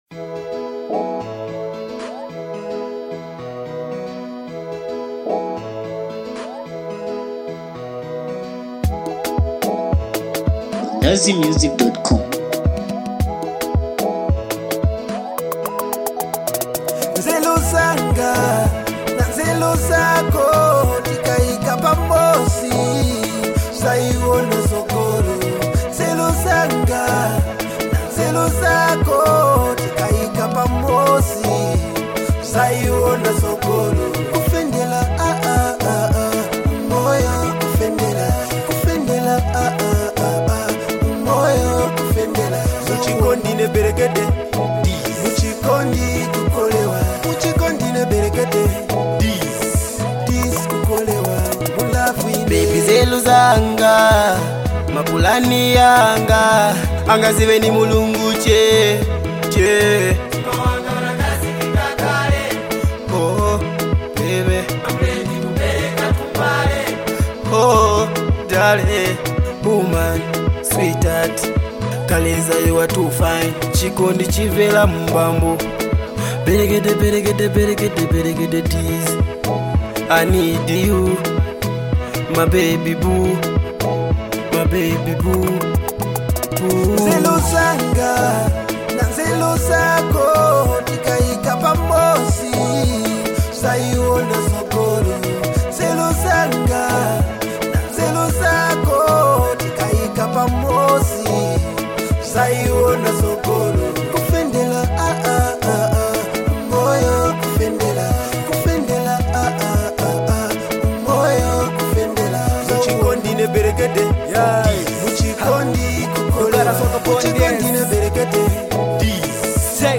Singer/producer